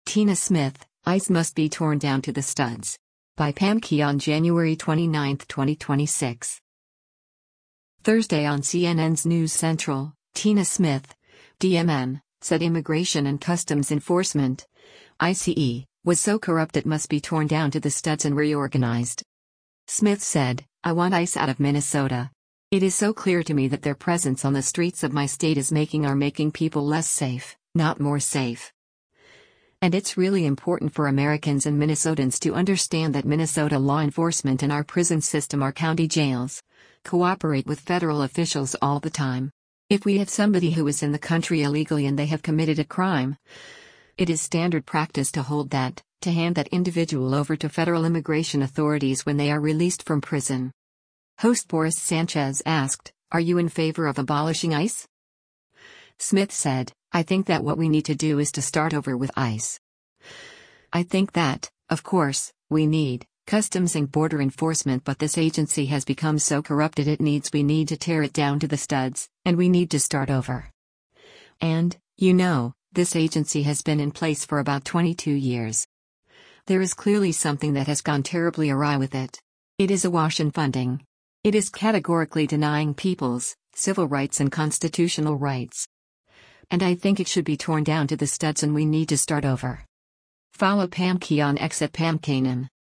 Thursday on CNN’s “News Central,” Tina Smith (D-MN) said Immigration and Customs Enforcement (ICE) was so corrupt it must “be torn down to the studs” and reorganized.
Host Boris Sanchez asked, “Are you in favor of abolishing ICE?”